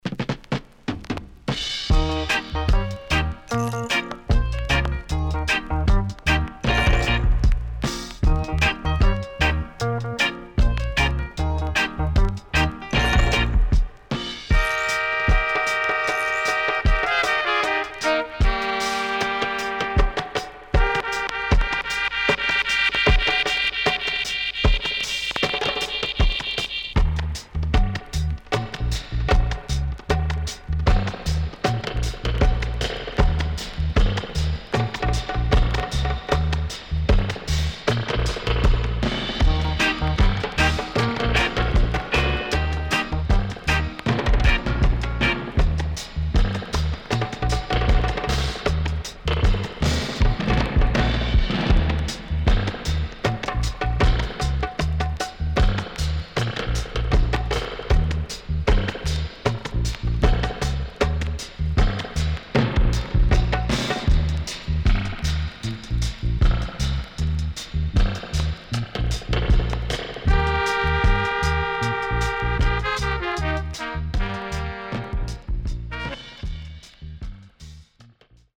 Great Horn Inst with Bongo Drum
SIDE A:少しチリノイズ、プチノイズ入ります。